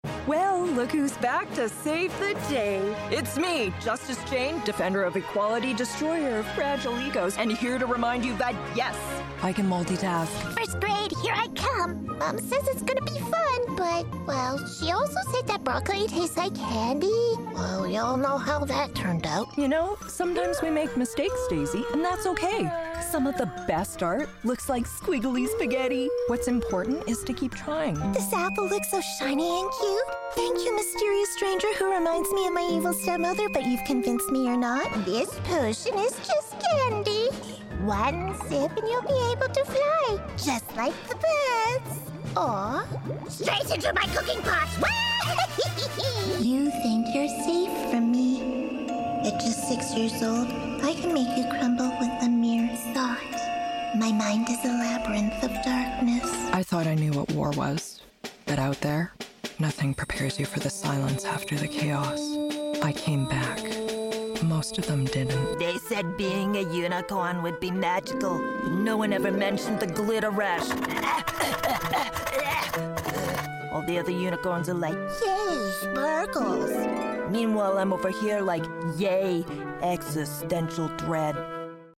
English (Canadian)
Animation
My extreme vocals and creature voices will literally scare the BLEEP outta you.
ACCENTS: Canadian, Standard American, Southern, Western, California, Pacific Northwest, Midwestern, British RP, TransAtlantic, French (Parisian and Quebecois), Scandinavian, Russian, Irish
Sennheiser MK4 mic